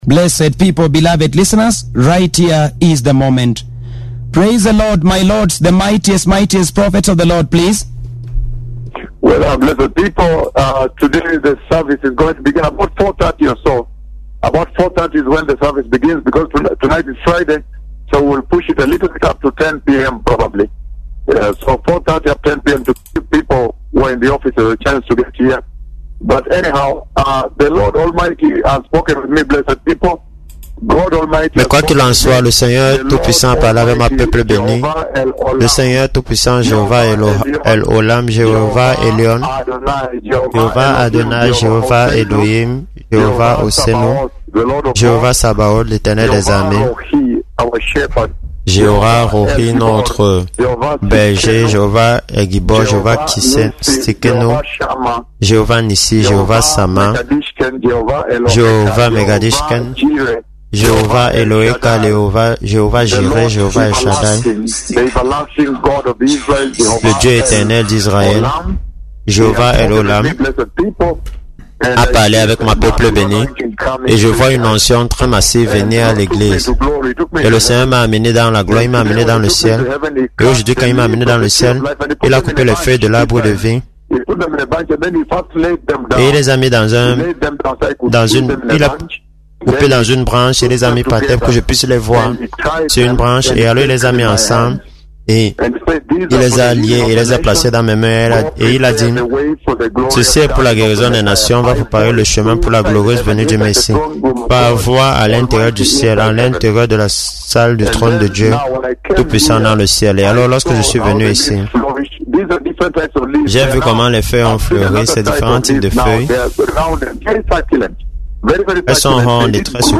Diffusion capturée depuis JESUS IS LORD RADIO et RADIO JÉSUS VIENT - Programme rediffusé sur RADIO VÉRITÉ.